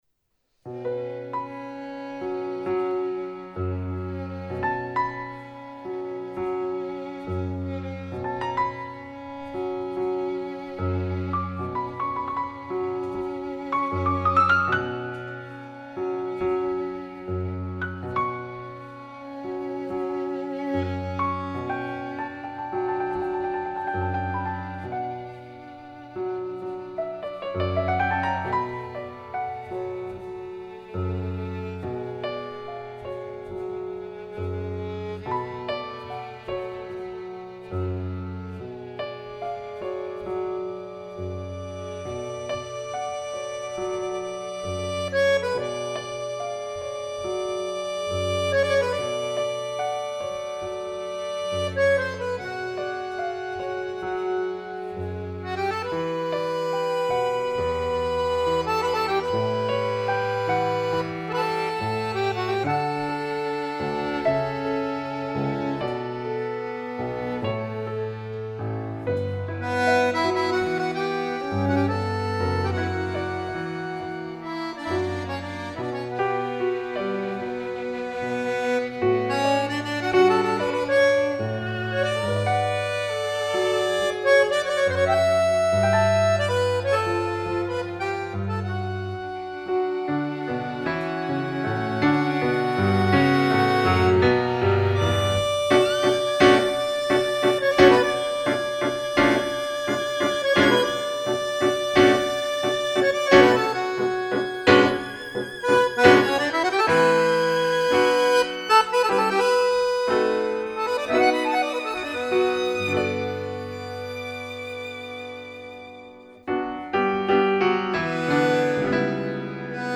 Fisarmonica, Violino e Pianoforte